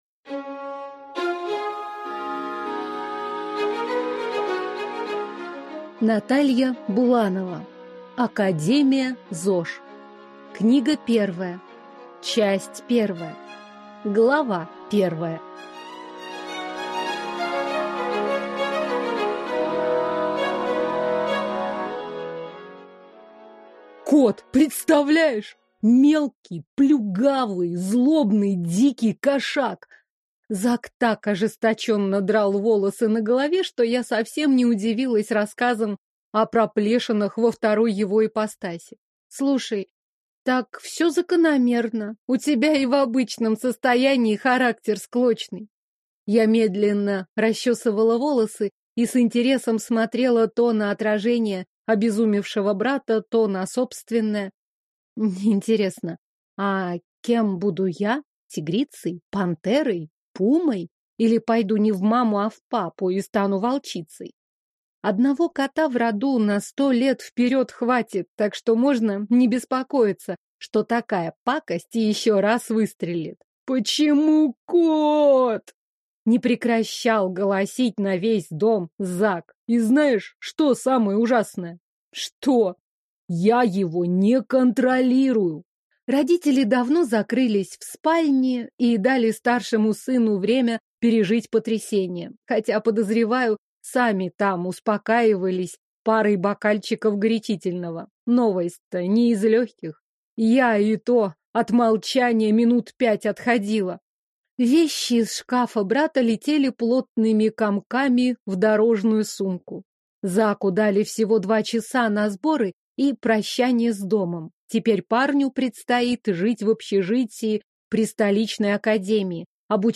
Аудиокнига Академия ЗОЖ. Книга 1 | Библиотека аудиокниг